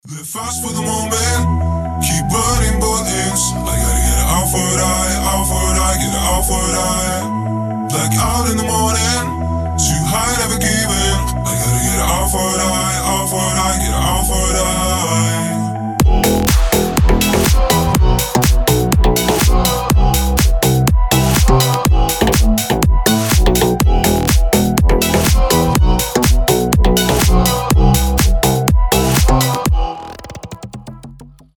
• Качество: 320, Stereo
громкие
мощные басы
качающие
ремиксы
slap house